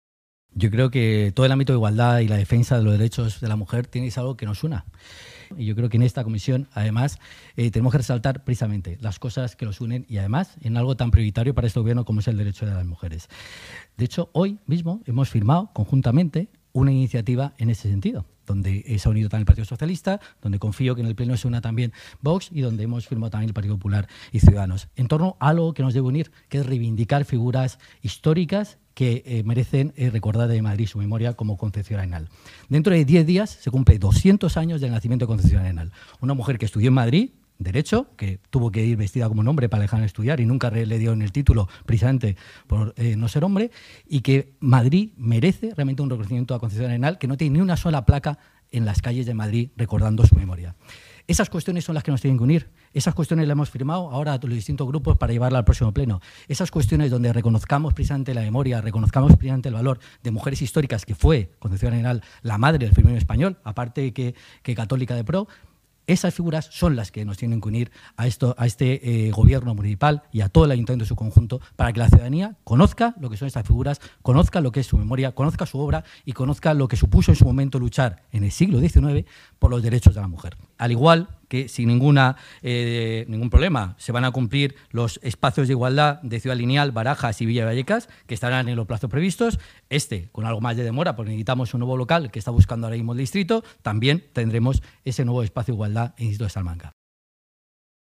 La red de espacios de igualdad del Ayuntamiento de Madrid incluirá entre sus actividades de 2020 difundir la figura de Concepción Arenal, pionera del feminismo español, cuando se cumplen 200 años de su nacimiento. Así lo ha anunciado el delegado de Familias, Igualdad y Bienestar Social, Pepe Aniorte, en la comisión permanente de su área.